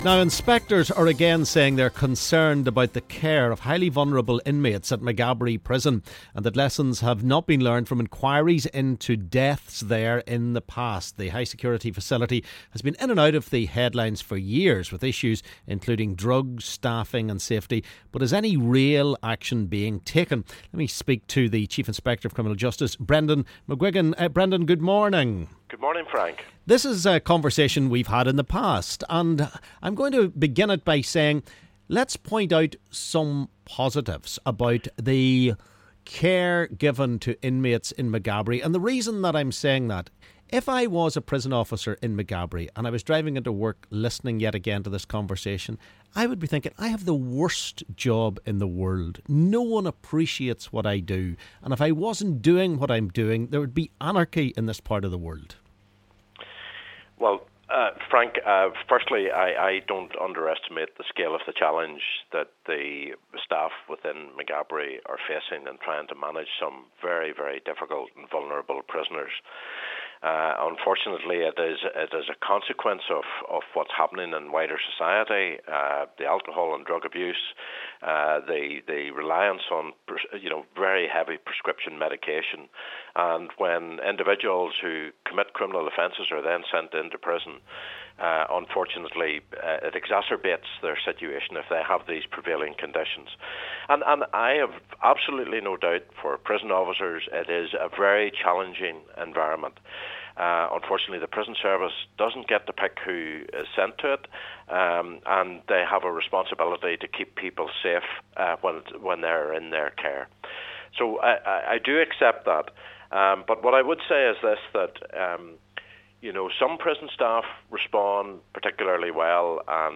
Two ex-prisoners discuss their time in Maghaberry Prison.